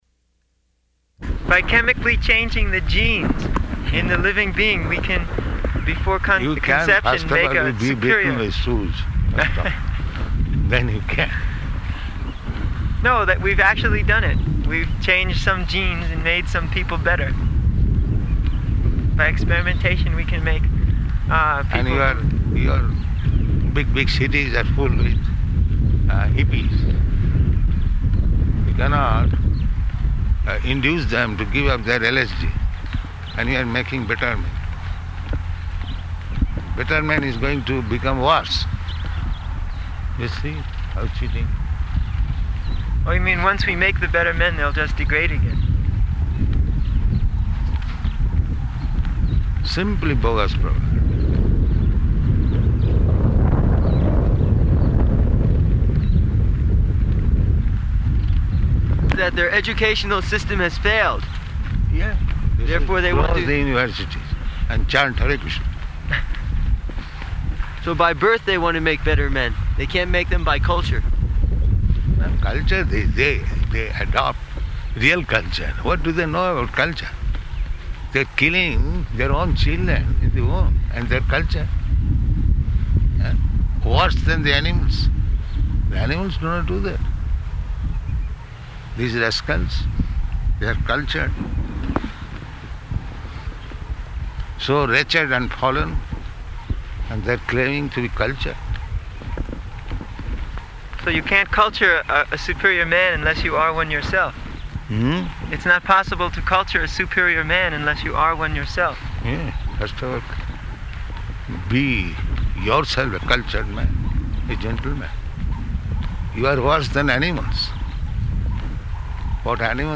Type: Walk
October 25th 1975 Location: Mauritius Audio file